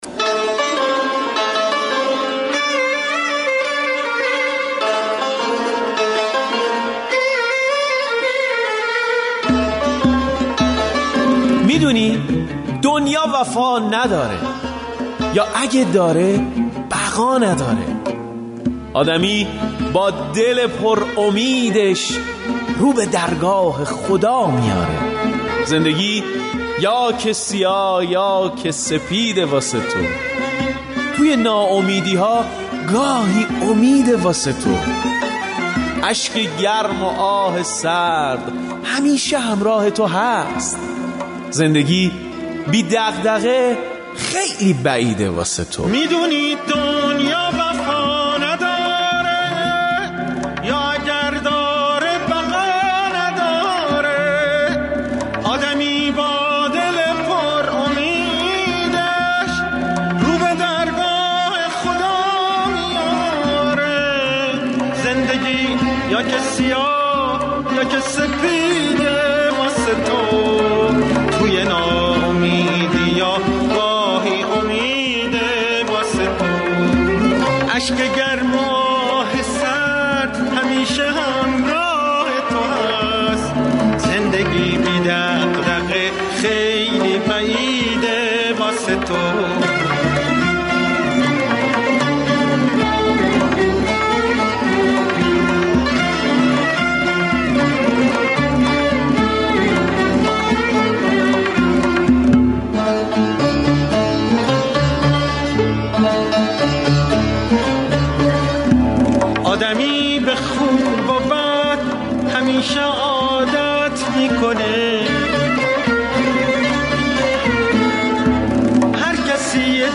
موزیک قدیمی
ترانه قدیمی